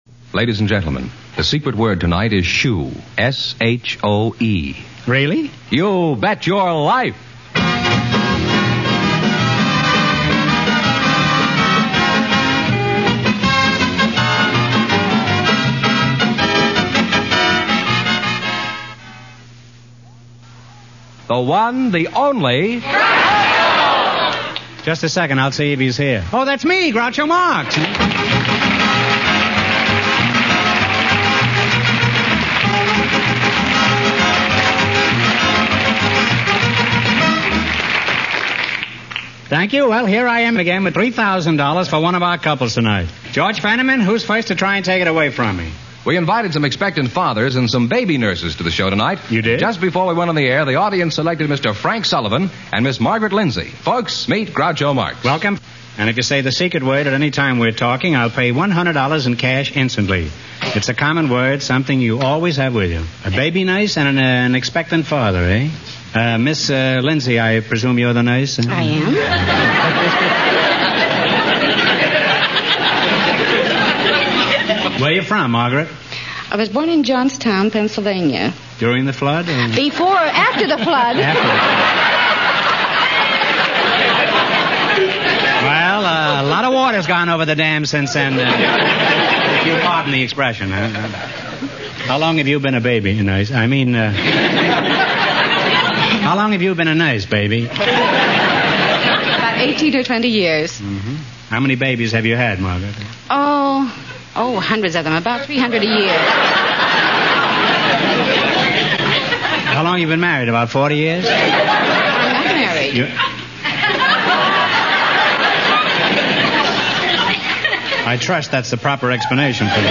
You Bet Your Life Radio Program, Starring Groucho Marx